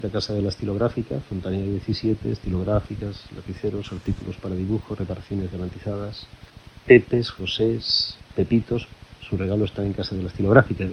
Recreació feta per Joaquim Maria Puyal en una entrevista feta a "L'altra ràdio" de Ràdio 4 el dia 1 de juliol de 2004.